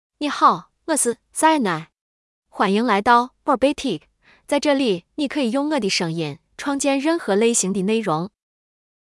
FemaleChinese (Zhongyuan Mandarin Shaanxi, Simplified)
Xiaoni — Female Chinese AI voice
Xiaoni is a female AI voice for Chinese (Zhongyuan Mandarin Shaanxi, Simplified).
Voice sample
Listen to Xiaoni's female Chinese voice.